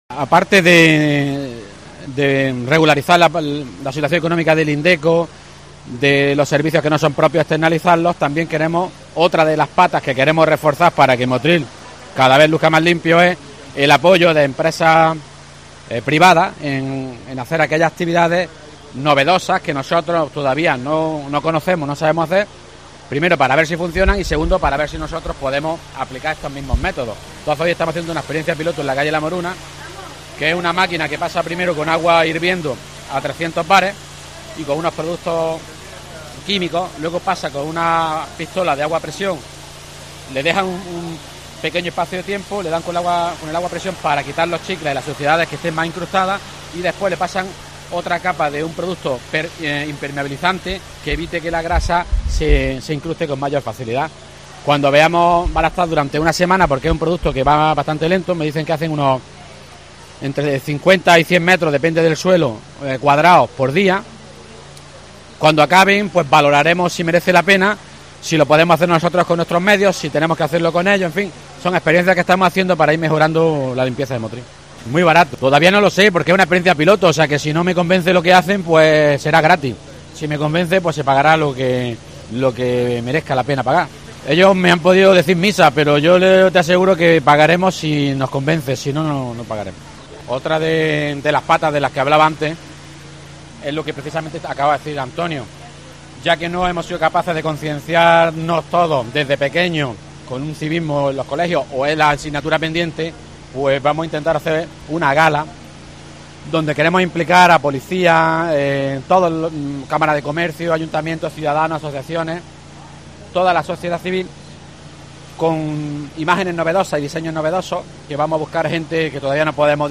Hablan los responsables municipales